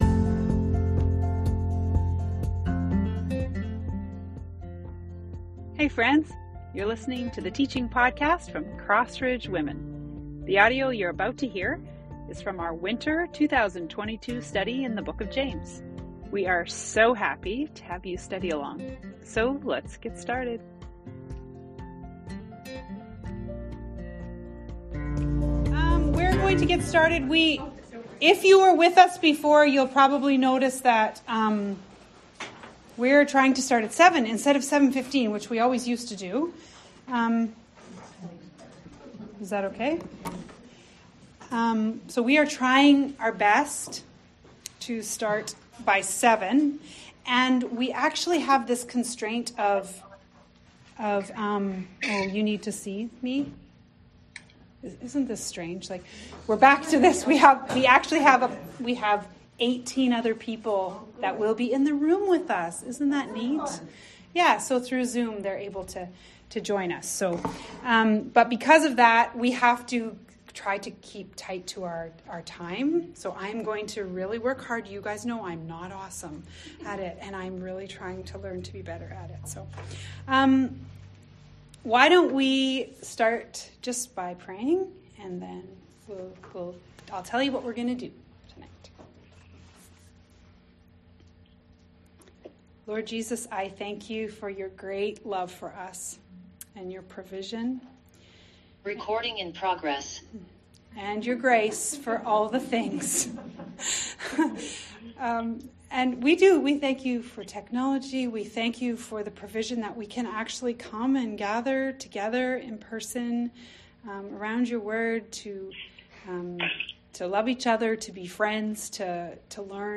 Teaching podcast from our James Study Intro night. Our vision and framework for this study, and a look forward at what we'll encounter in chapter 1.